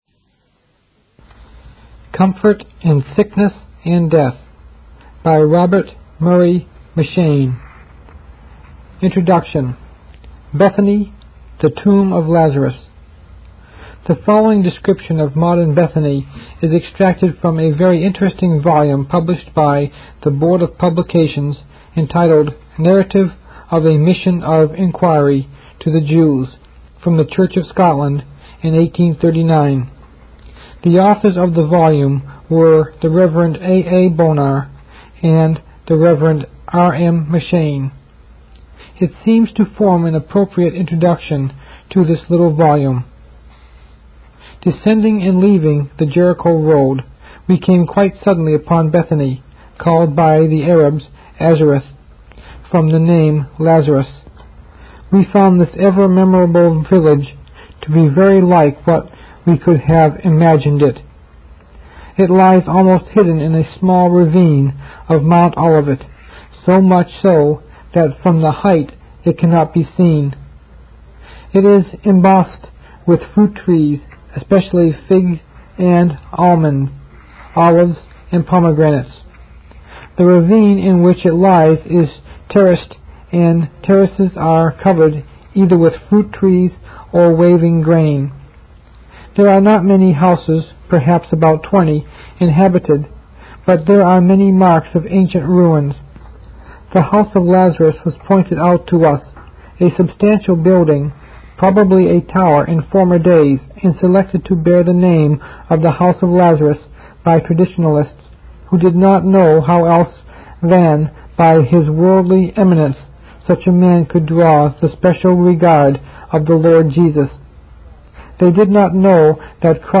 The Sickness, Death and Resurrection of Lazarus 1 of 4 (Reading) by Robert Murray M'Cheyne | SermonIndex